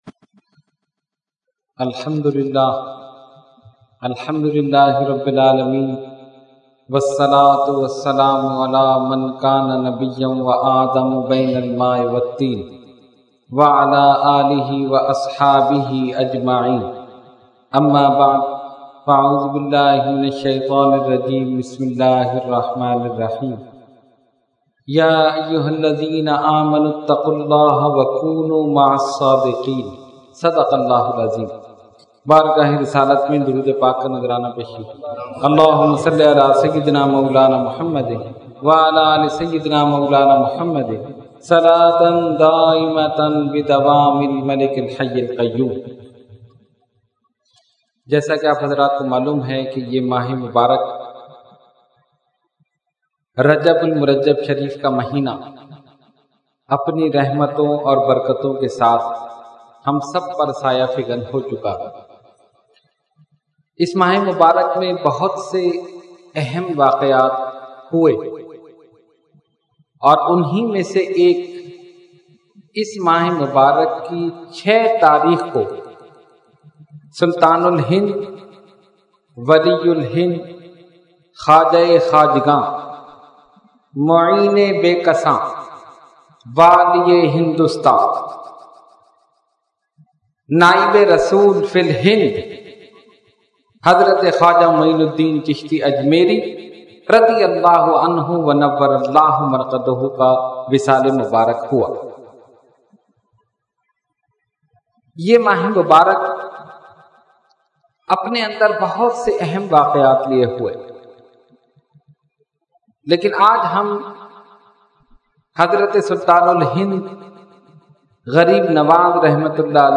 Category : Speech | Language : UrduEvent : Weekly Tarbiyati Nashist